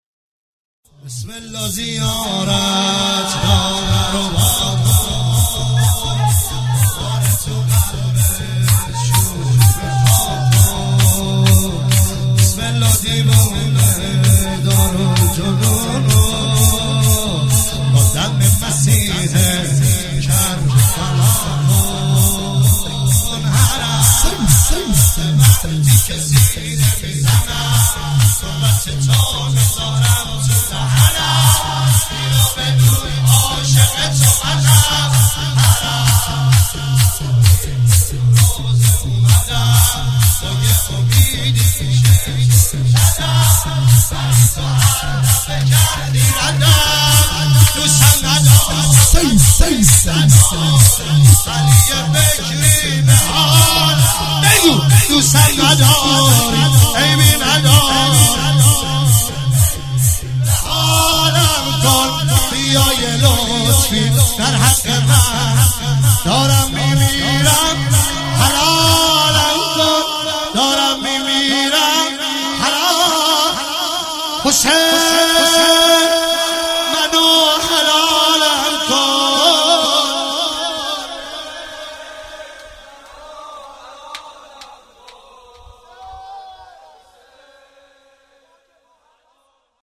عنوان استقبال از ماه مبارک رمضان ۱۳۹۸
شور